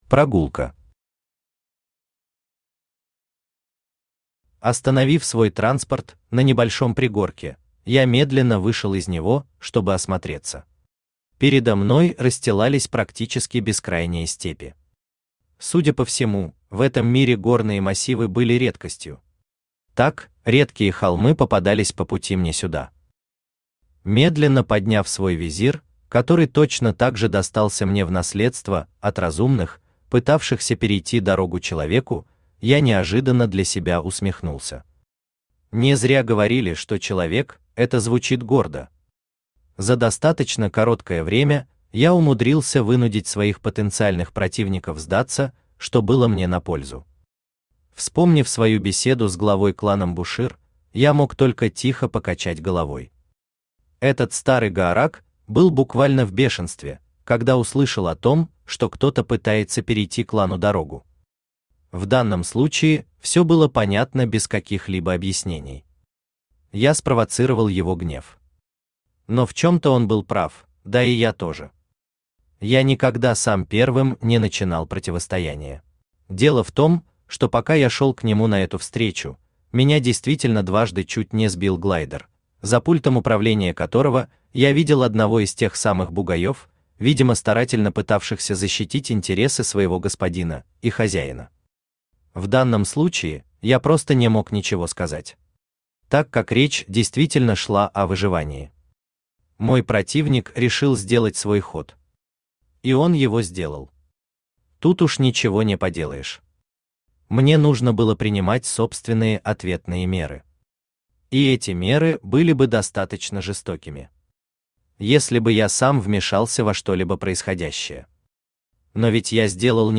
Поиск себя Автор Хайдарали Усманов Читает аудиокнигу Авточтец ЛитРес.